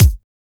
Retro Bd.wav